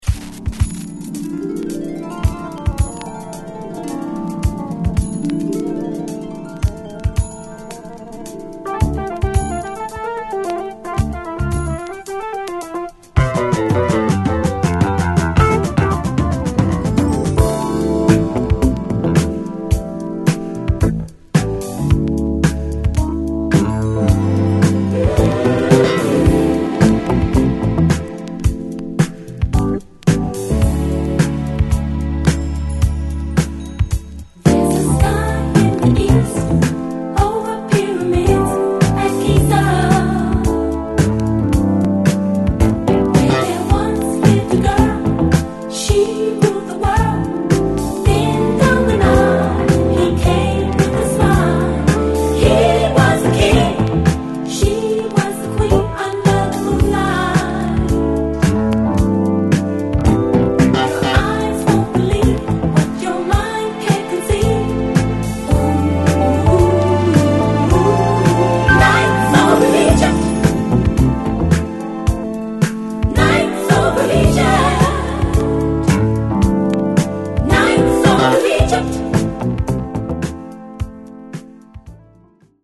Format: 7 Inch
※曲の頭の部分で目立つノイズが入ります。
※この盤からの録音ですので「試聴ファイル」にてご確認下さい。